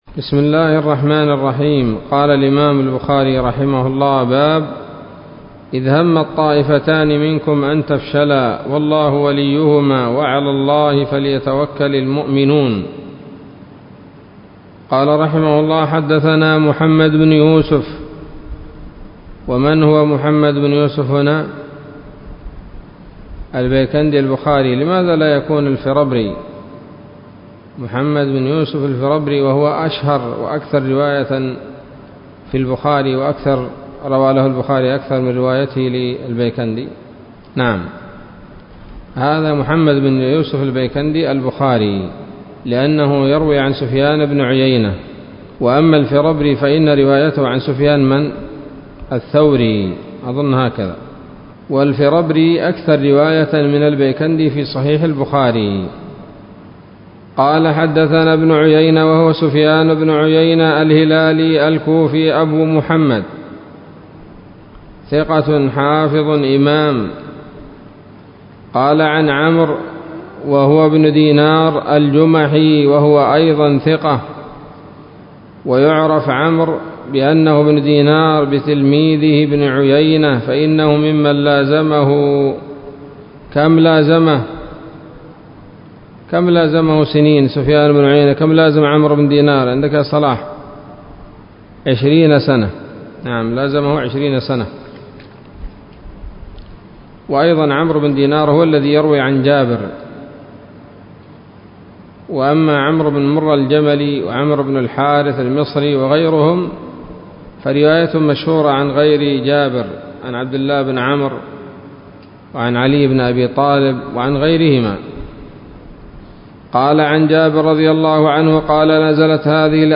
الدرس الرابع والثلاثون من كتاب المغازي من صحيح الإمام البخاري